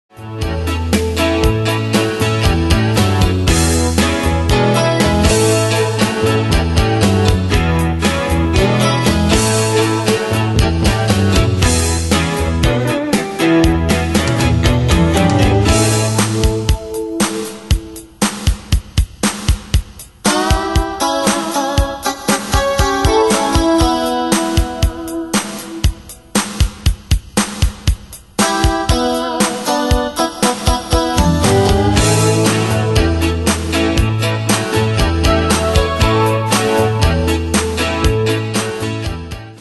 Style: Country Année/Year: 1992 Tempo:118 Durée/Time: 3.24
Pro Backing Tracks